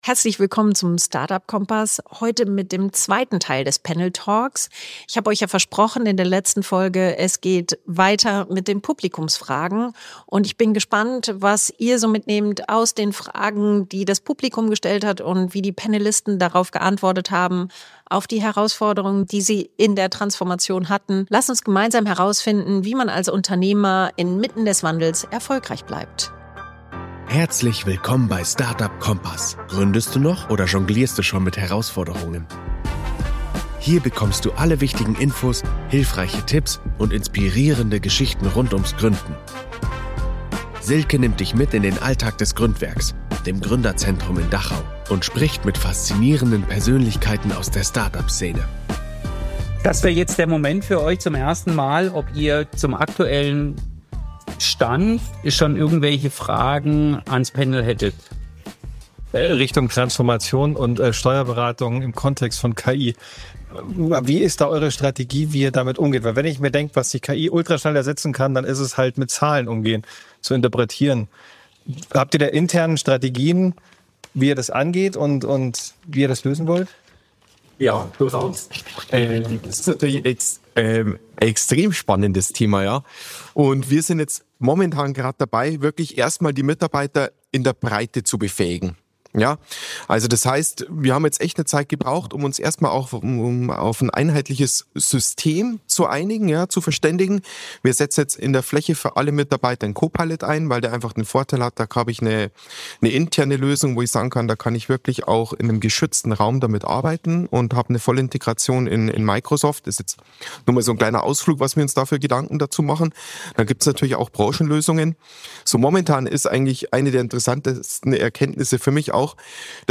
Fünf UnternehmerInnen – ein Panel. In dieser Folge des StartUp Kompass hörst du den 2. Teil des Live-Mitschnitts unseres Transformation-Events im Gründwerk. Es geht um echte Veränderung, Leadership im Wandel und darum, wie Unternehmen heute zukunftsfähig bleiben – ganz ohne Buzzwords.